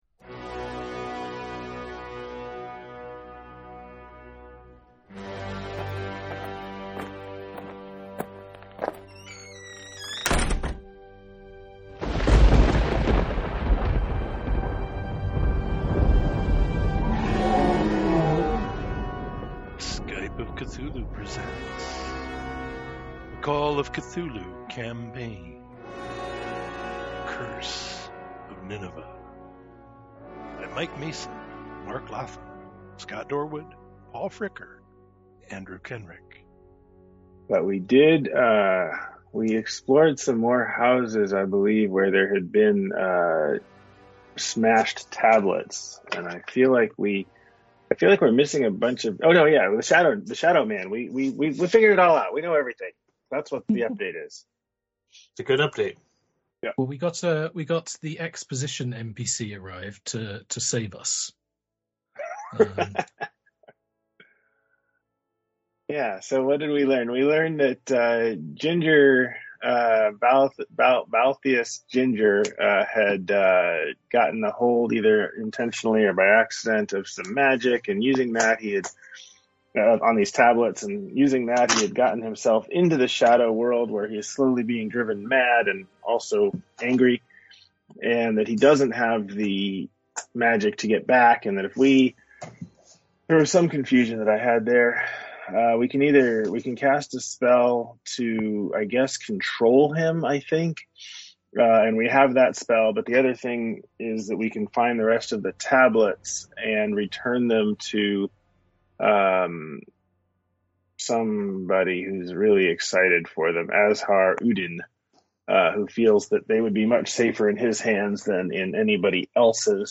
Skype of Cthulhu presents a Call of Cthulhu scenario.